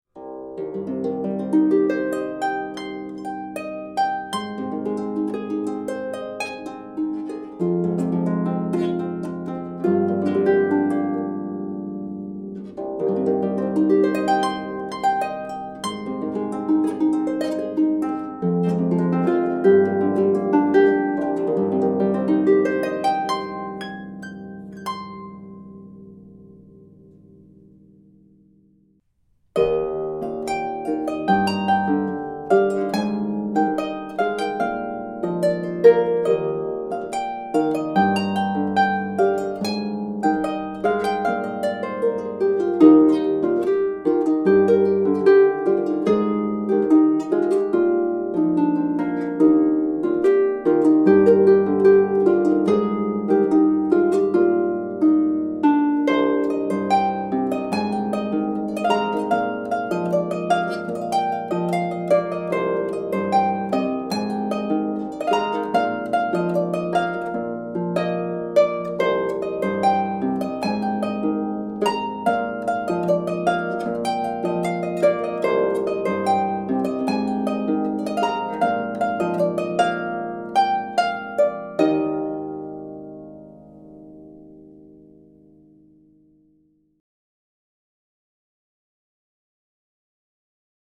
Keltische Harfe 34 Saiten
Tonumfang C - a ´´´
Das Instrument besitzt einen vollen Klang mit starken Bässen und bietet die Möglichkeit eines schnellen, dynamischen Spiels.